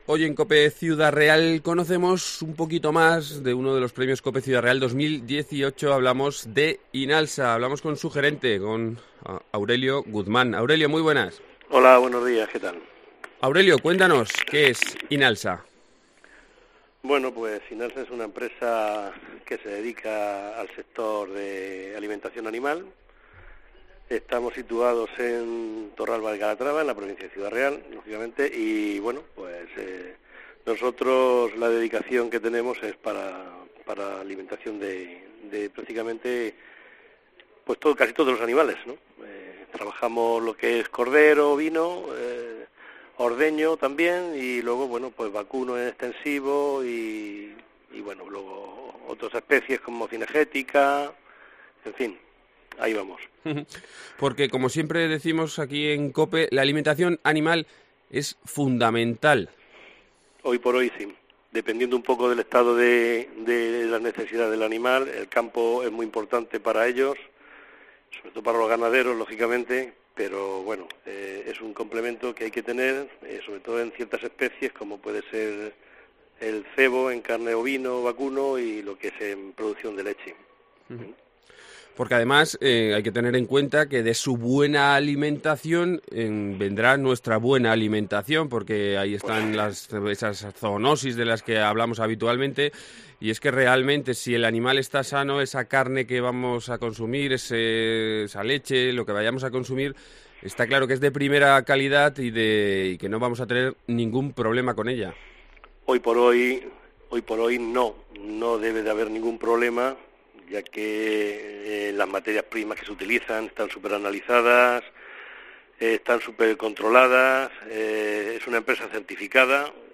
Y aquí en Ciudad Real tenemos a una empresa líder en el sector de la alimentación animal.